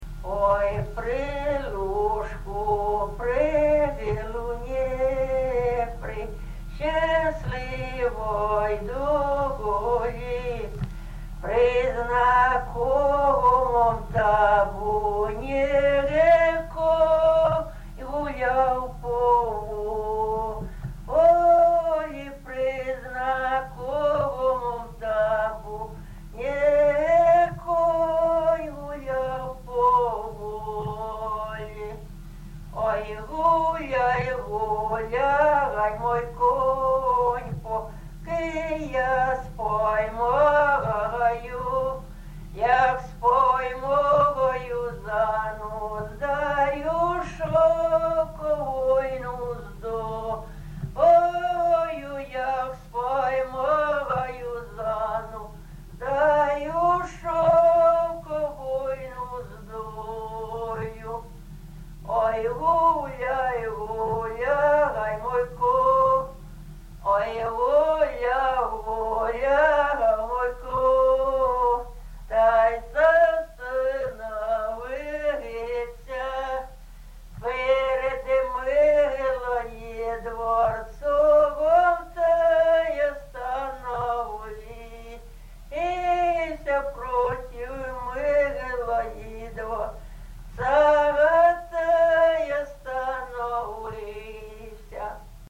ЖанрПісні з особистого та родинного життя
Місце записус. Софіївка, Краматорський район, Донецька обл., Україна, Слобожанщина